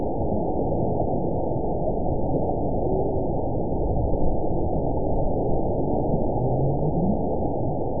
event 921774 date 12/18/24 time 23:22:20 GMT (4 months, 3 weeks ago) score 9.40 location TSS-AB02 detected by nrw target species NRW annotations +NRW Spectrogram: Frequency (kHz) vs. Time (s) audio not available .wav